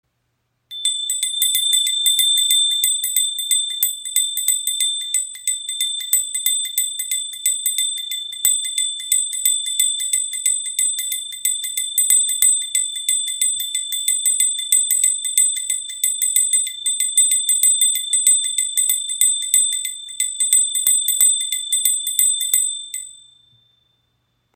• Icon Reiner Klang – Klarer, heller Ton für Meditation & Rituale
Eine kleine Handbewegung, ein heller, vibrierender Ton – die Nandi-Handglocke entfaltet ihren Klang und schafft eine Atmosphäre der Klarheit und Achtsamkeit.
• Material: Messing